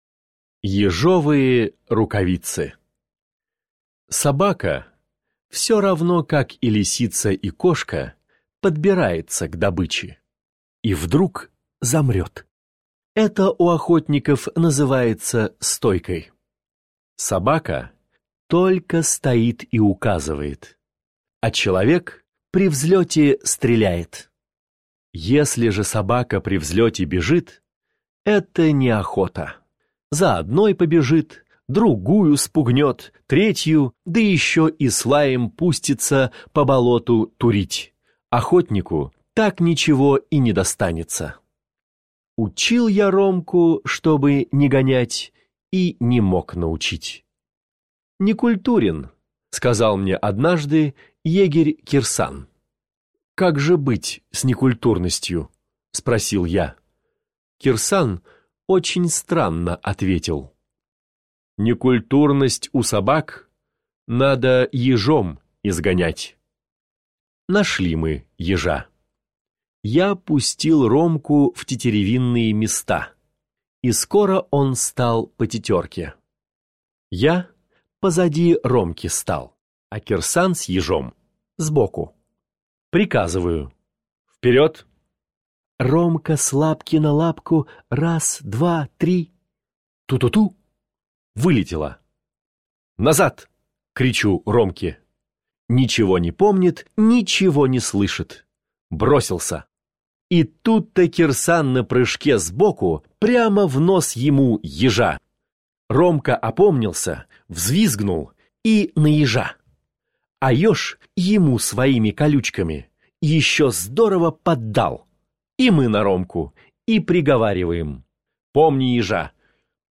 Ежовые рукавицы – Пришвин М.М. (аудиоверсия)
Аудиокнига в разделах